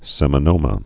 (sĕmə-nōmə)